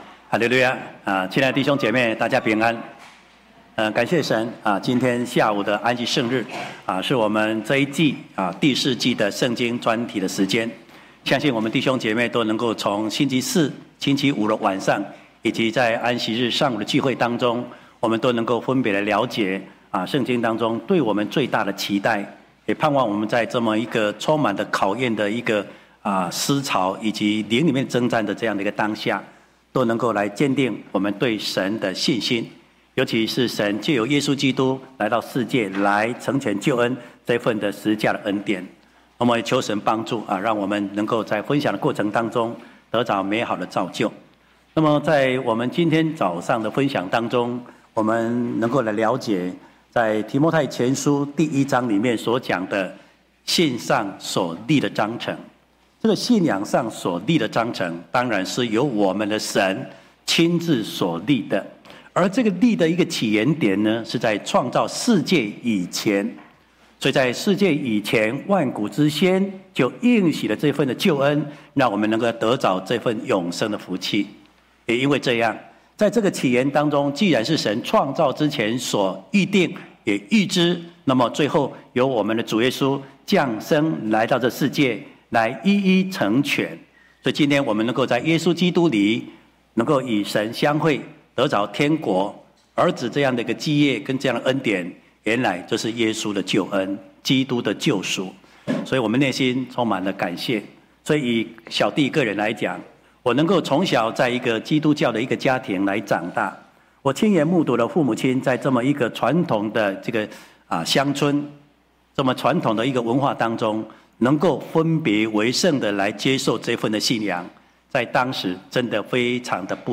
聖經專題：信上所立的章程（四）-講道錄音